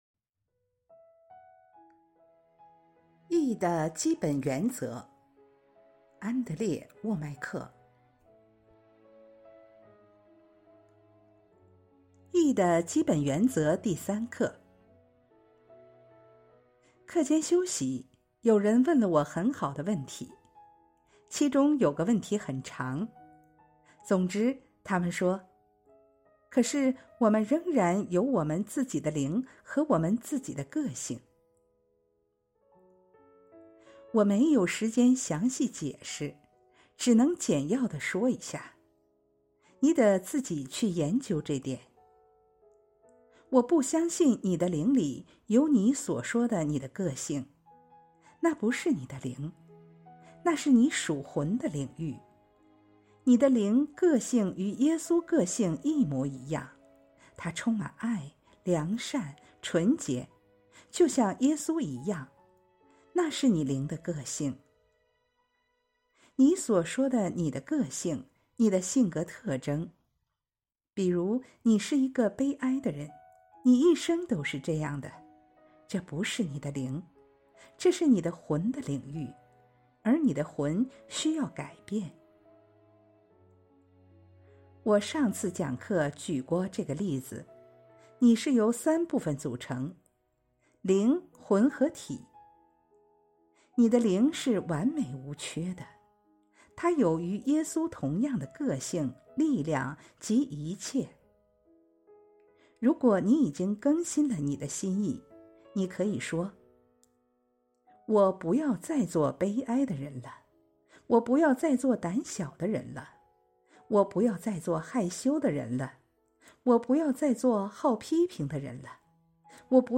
义的基本原则 有声书